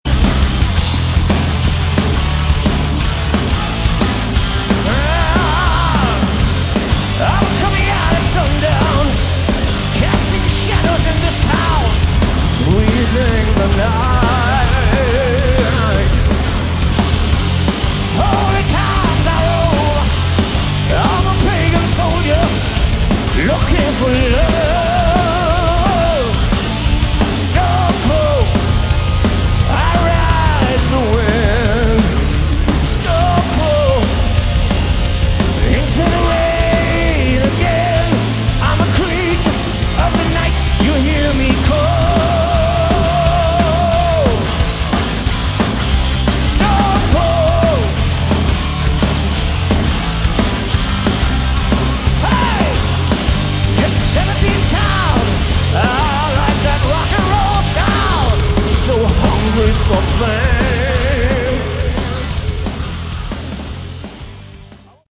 Category: Melodic Metal
vocals
guitar
keyboards
bass
drums